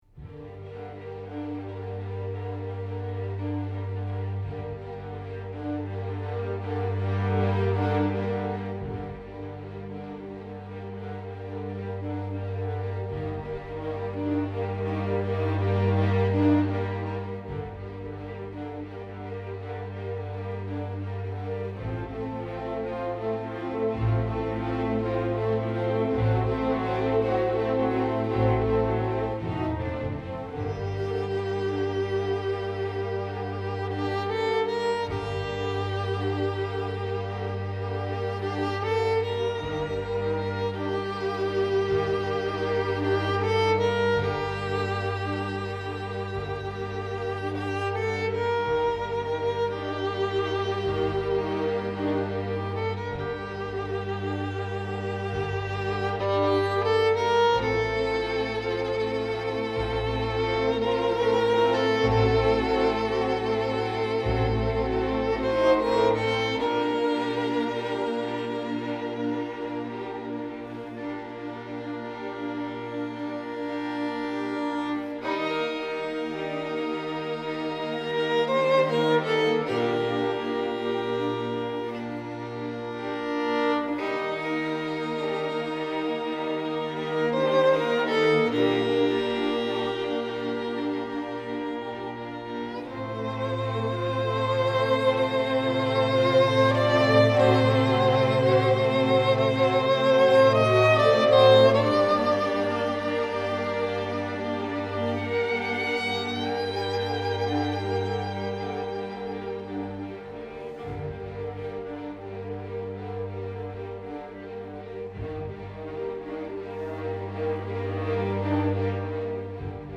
for violin and orchestra